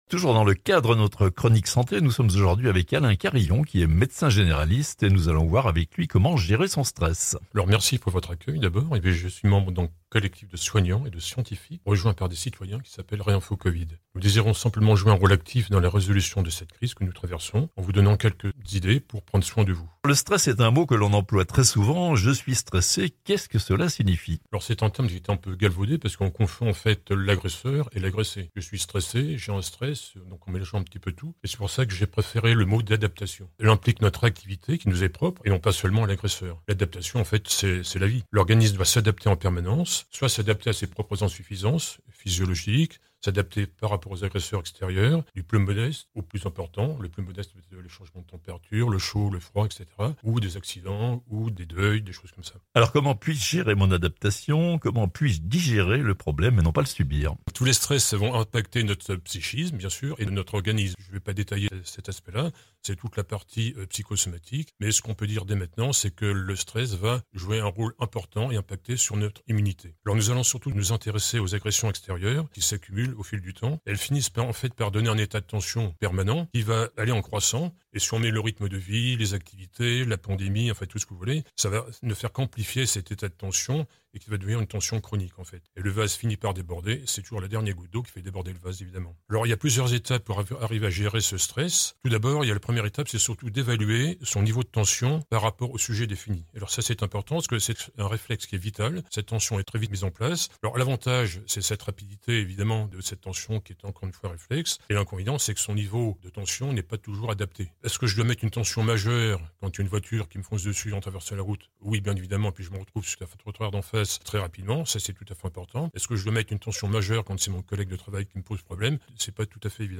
Magazine santé : comment gérer son stress (interview)